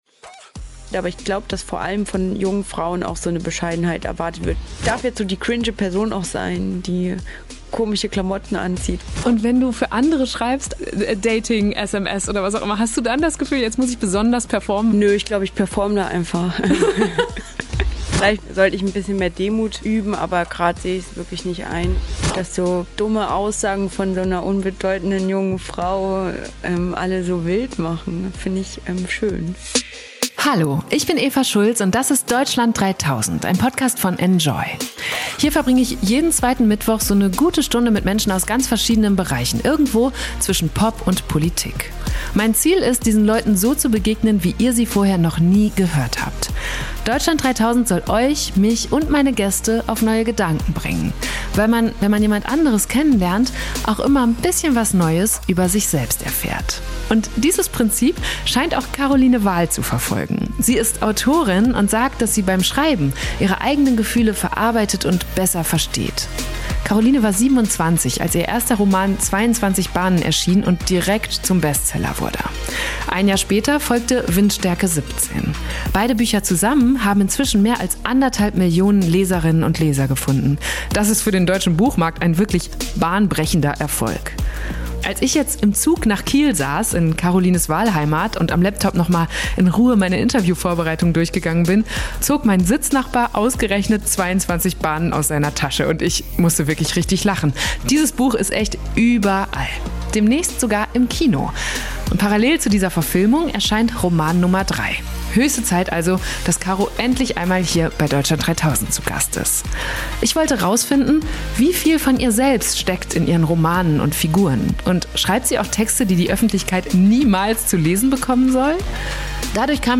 Höchste Zeit, dass Caro endlich einmal hier zu Gast ist. Ich wollte herausfinden: Wie viel von ihr selbst steckt in ihren Romanen und Figuren?